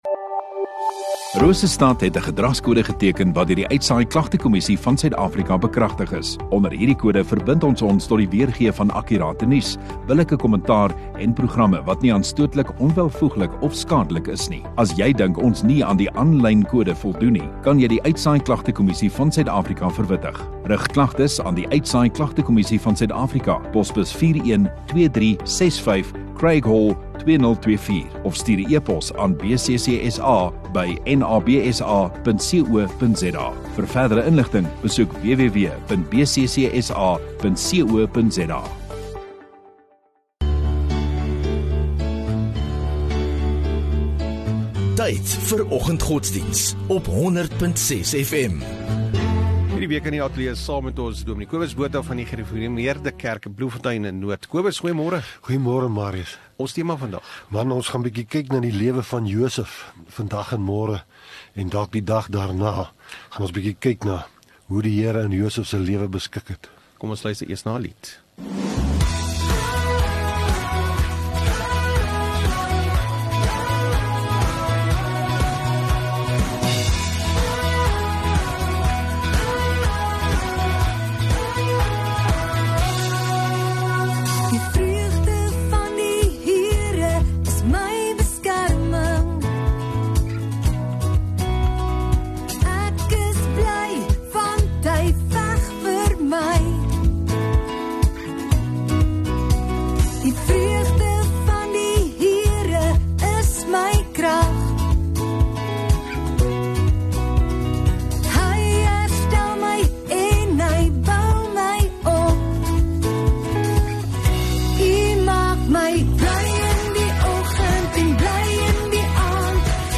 9 Sep Maandag Oggenddiens